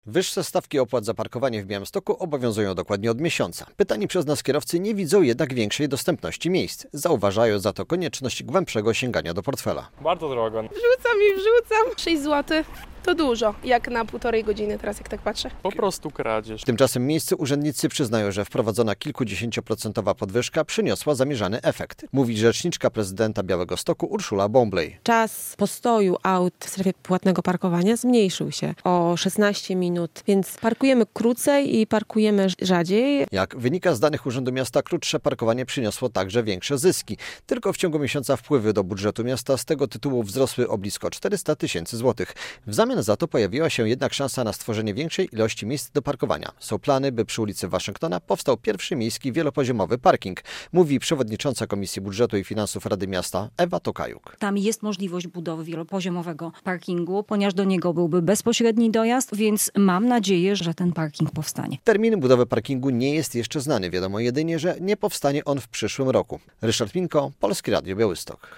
Wiadomości - Minął miesiąc od podwyższenia opłat za parkowanie w centrum Białegostoku - czy przyniosło to oczekiwane zmiany?